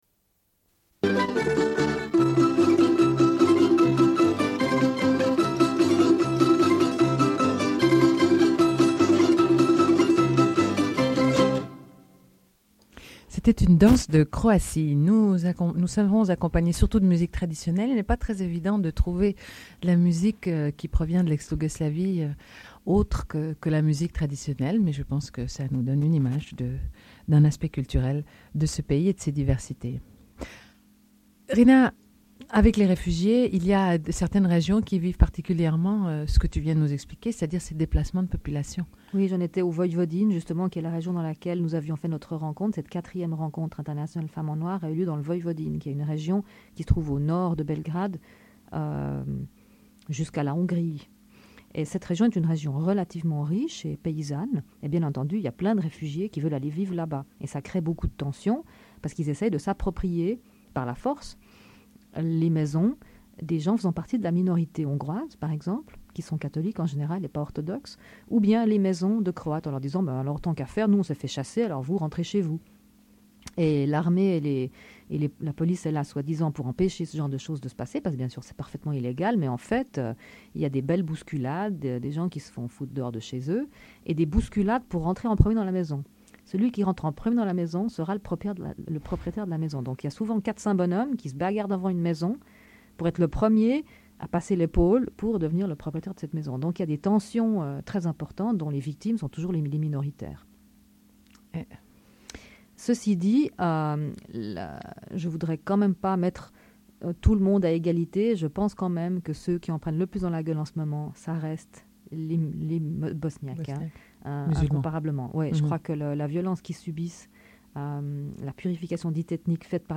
Suite de l'émission : en direct avec
Une cassette audio, face B
Radio